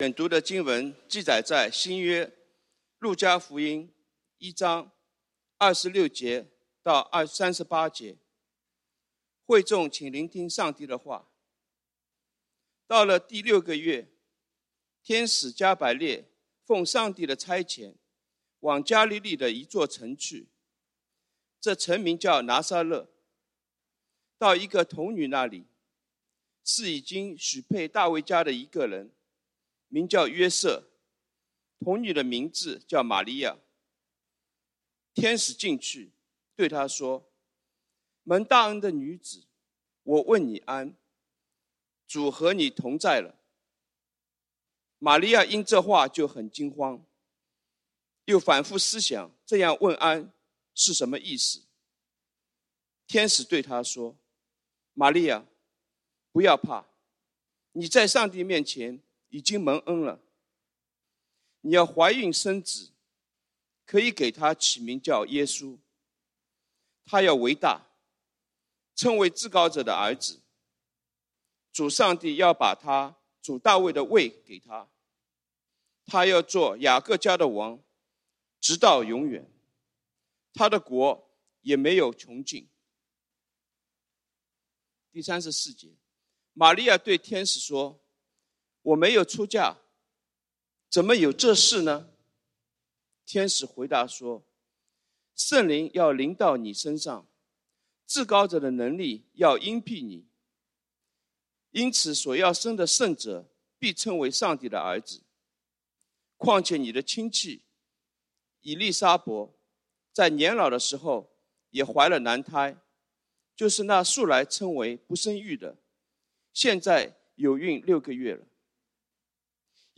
講道經文：《路加福音》Luke 1:26-38 本週箴言：《提摩太前書》1 Timothy 3:16 「大哉，敬虔的奧祕！